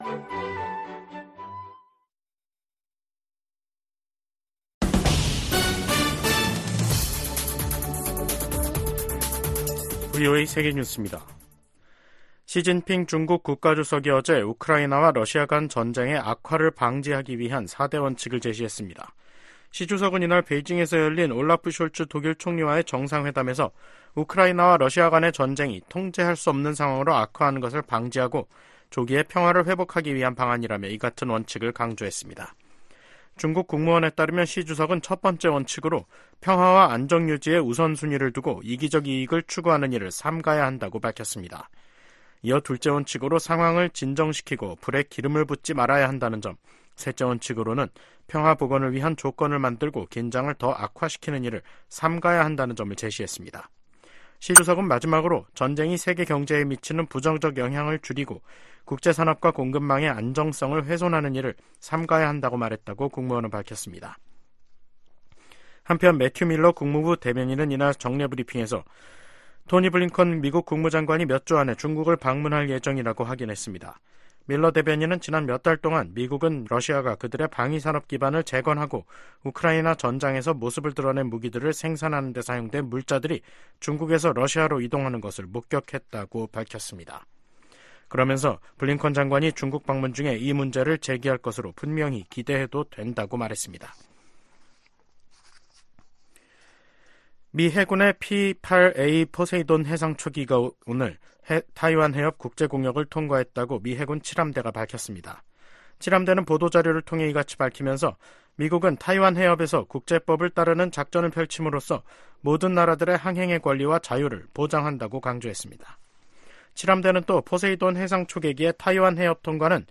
VOA 한국어 간판 뉴스 프로그램 '뉴스 투데이', 2024년 4월 17일 2부 방송입니다. 미국은 북한과 이란의 군사 협력 가능성을 우려하며 중동뿐 아니라 인도태평양 지역 파트너와 긴밀히 협력해 대응할 것이라고 국방부 대변인이 말했습니다. 한국은, 이스라엘 방공 전략서 교훈 얻어야하며, 미한일 통합 미사일 방어망 구축 필요하다고 전문가들이 제언했습니다.